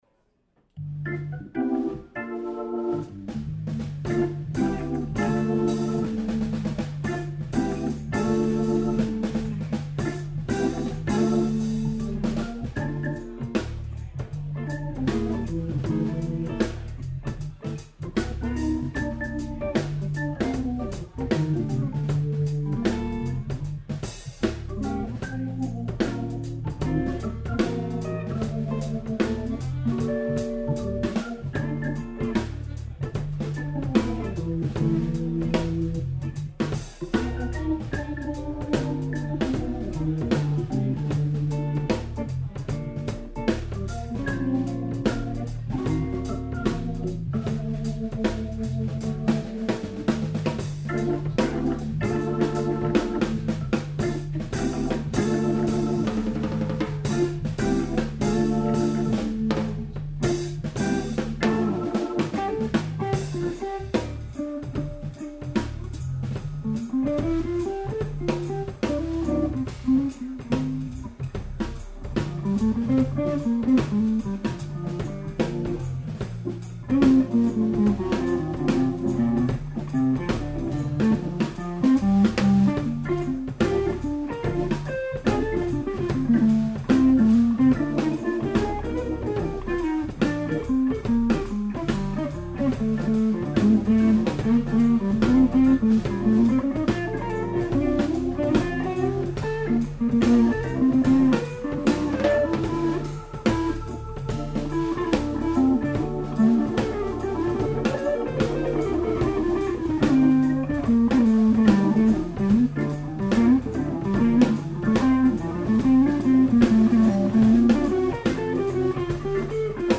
guitar
It's all good stuff and the guitar work is amazing.